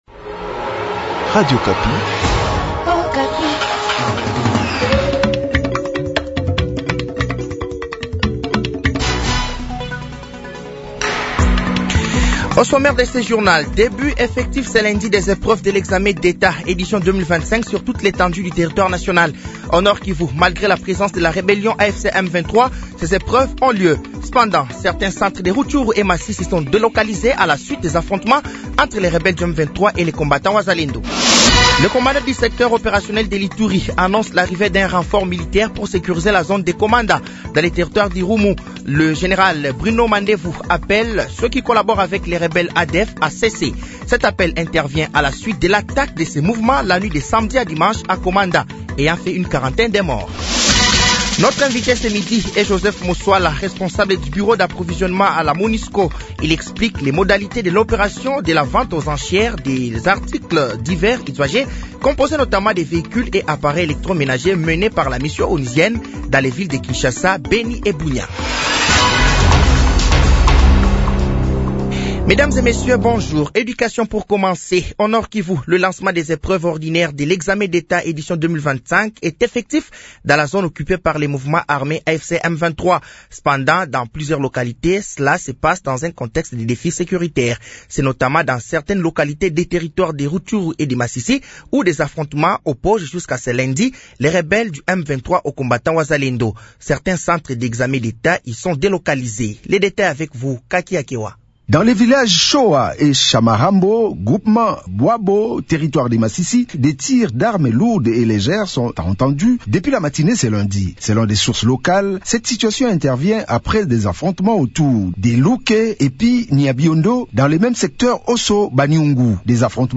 Journal français de 12h de ce lundi 28 juillet 2025